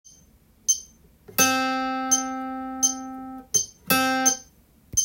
３拍伸ばして裏
譜面は全てドの音だけで表記していますので
３つ目のリズムは、まず付点２分音符を弾き